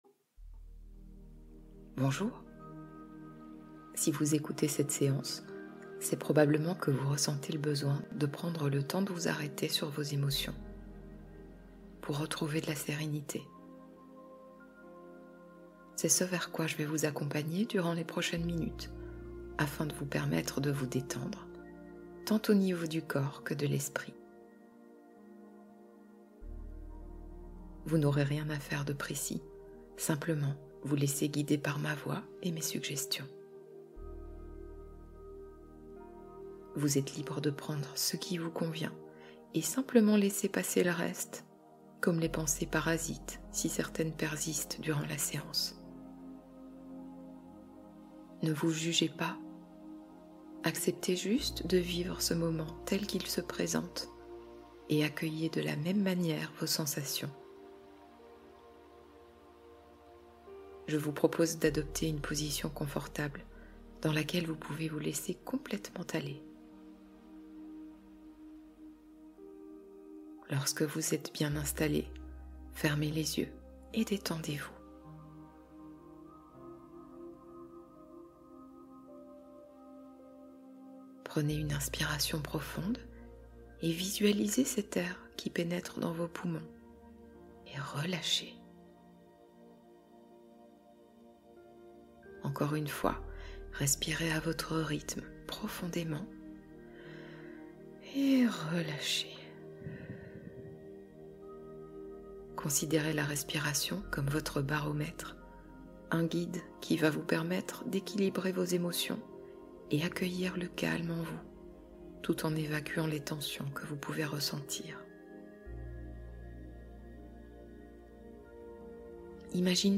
Vers le pays des rêves : hypnose pour un sommeil doux